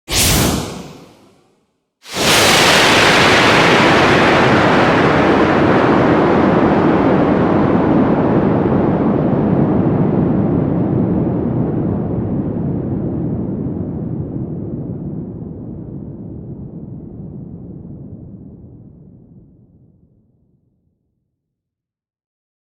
Free SFX sound effect: Rocket Ignition And Takeoff.
Rocket Ignition And Takeoff
Rocket Ignition and Takeoff.mp3